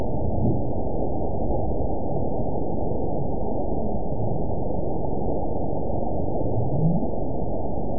event 918002 date 04/26/23 time 15:10:59 GMT (2 years ago) score 9.05 location TSS-AB04 detected by nrw target species NRW annotations +NRW Spectrogram: Frequency (kHz) vs. Time (s) audio not available .wav